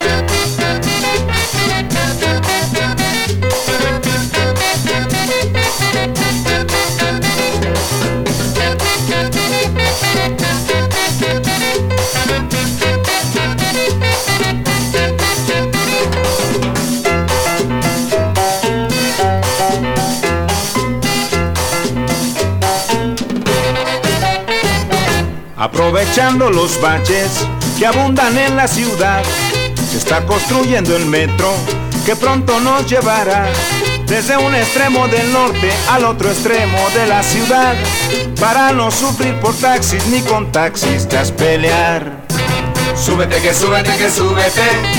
Latin, Cumbia, Boogaloo　USA　12inchレコード　33rpm　Mono